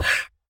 mob / horse / donkey / hit1.ogg
should be correct audio levels.